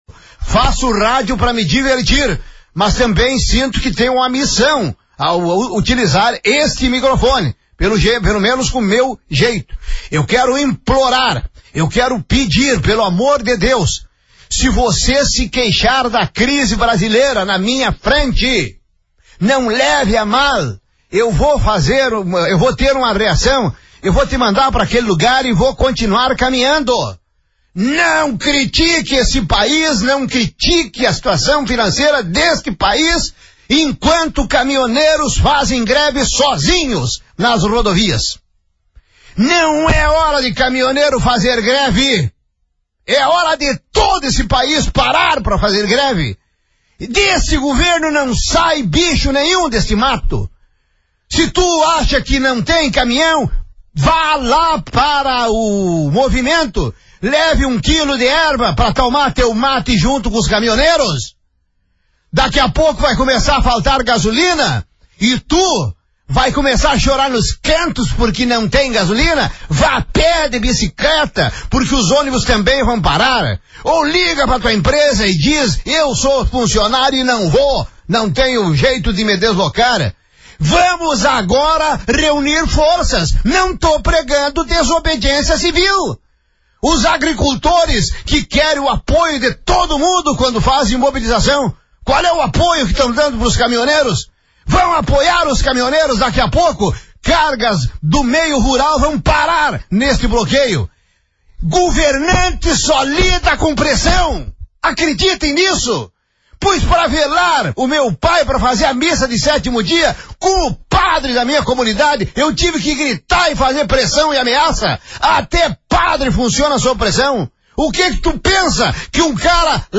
Locutor Gaúcho Falando Sobre os Caminhoneiros